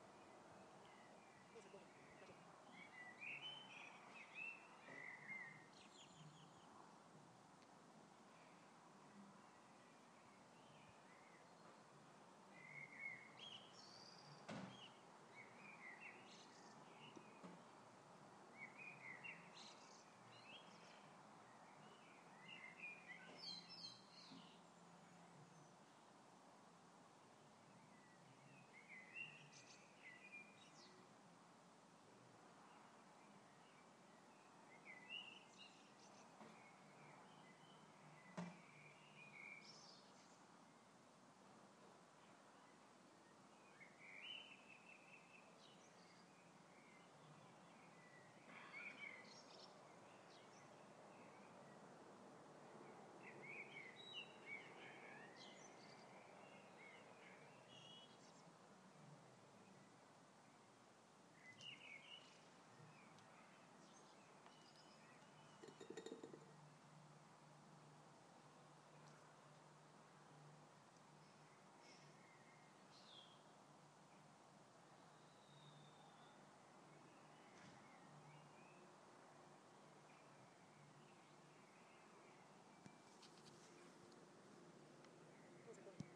Sounds of the birds in my garden. Oh and the a38 in the background lol.